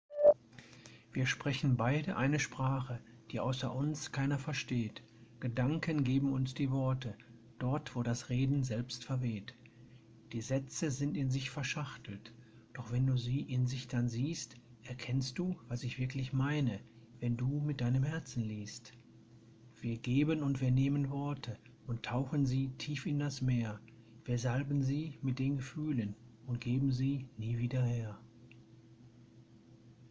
Fremdgelesen: Ein Gedicht, welches gehört werden sollte..
Ein Gedicht, gelesen und selbstverstehtsich auch geschrieben vom Autor und Blogger selbst, welcher aber zunächst anonym verbleiben möchte.